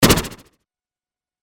/ F｜演出・アニメ・心理 / F-25 ｜Impact アタック
アタック
synth1